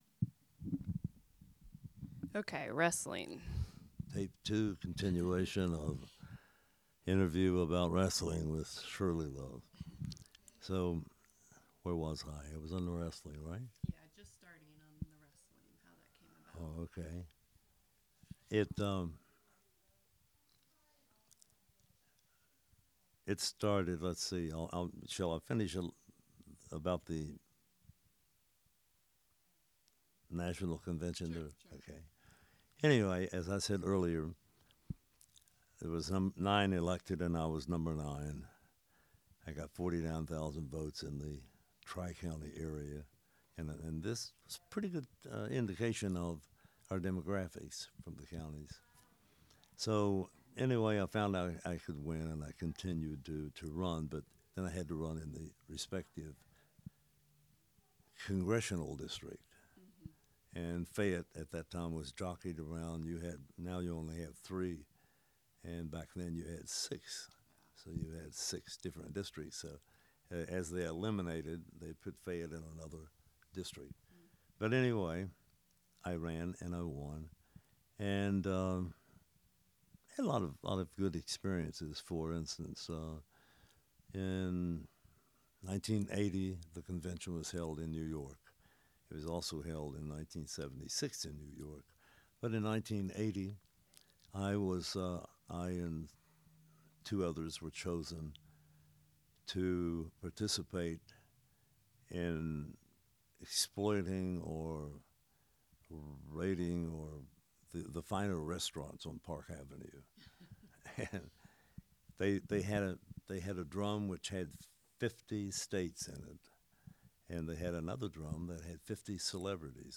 Oral history of Shirley Love, 5 of 5